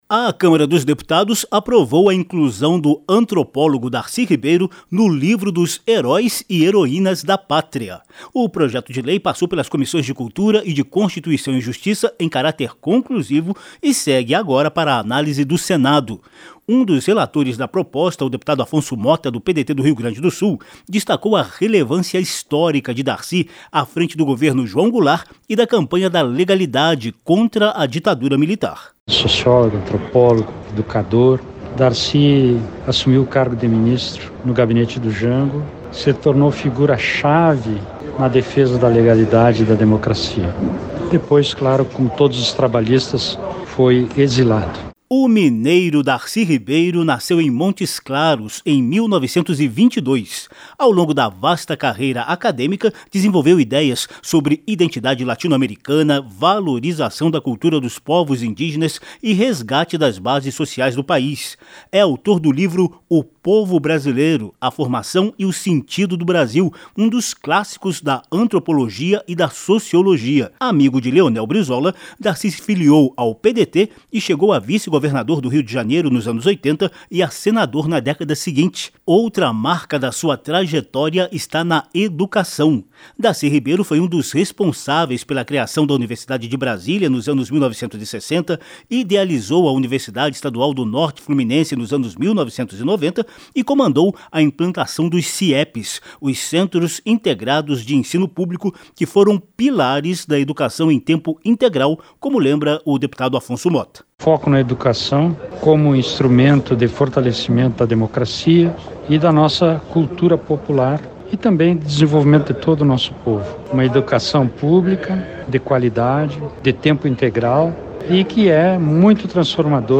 PROPOSTA APROVADA NA CÂMARA ELEVA DARCY RIBEIRO A “HERÓI DA PÁTRIA”. O REPÓRTER